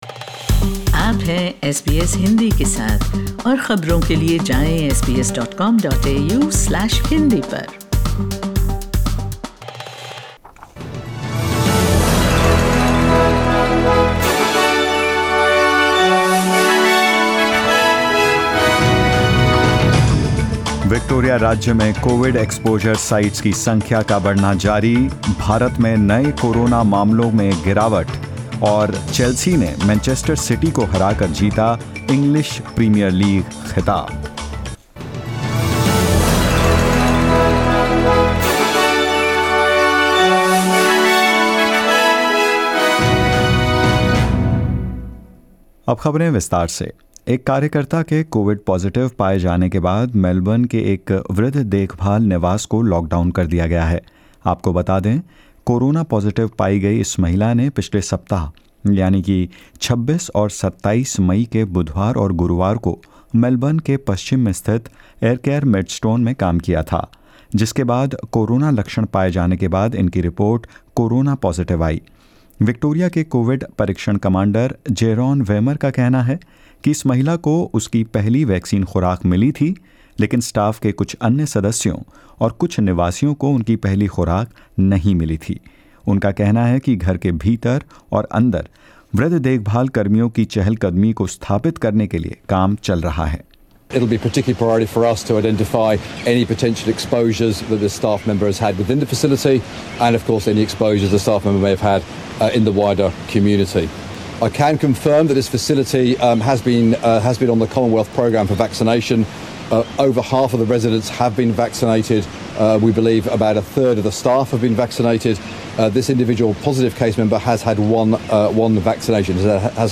In this latest SBS Hindi News bulletin of Australia and India: The Prime Minister leaves behind virus controversy for talks across the Tasman in New Zealand; Chelsea wins the Champions League and more. 30/5/21